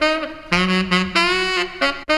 BTS SAXD#01R.wav